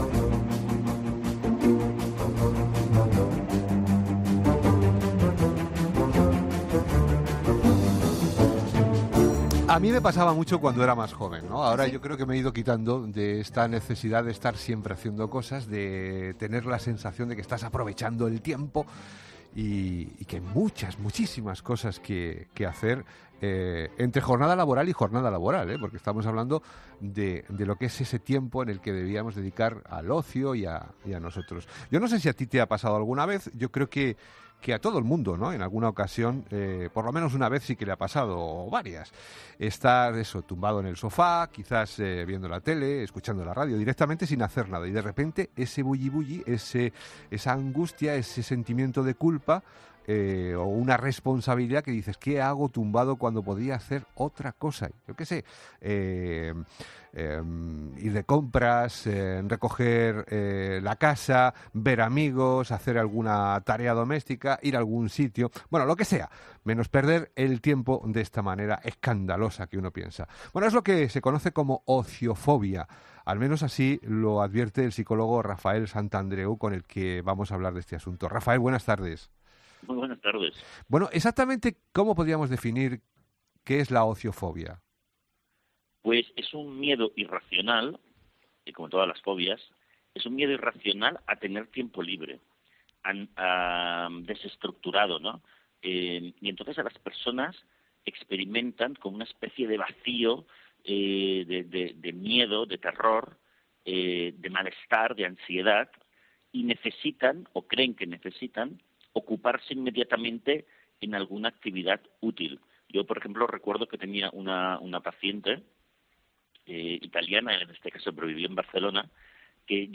ESCUCHA LA ENTREVISTA COMPLETA | Rafael Santandreu en 'Herrera en COPE'